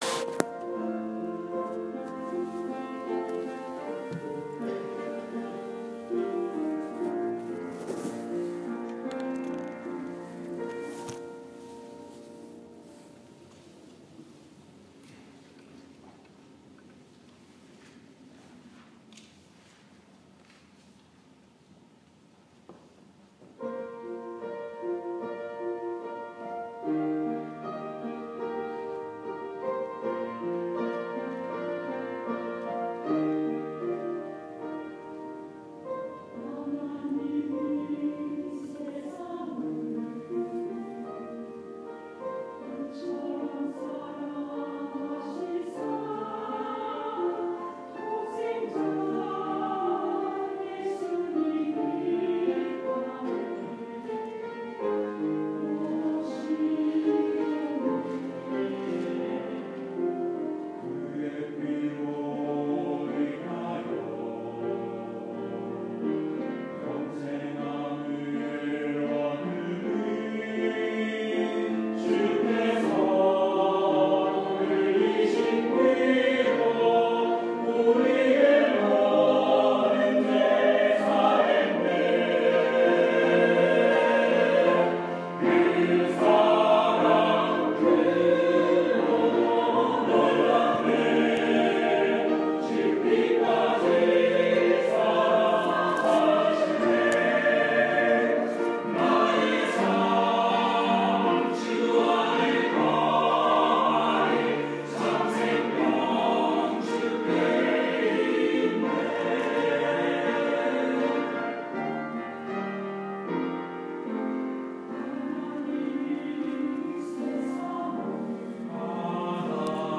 3월 13일 주일 찬양대(그 사랑 크고 놀랍네, 홍지열곡)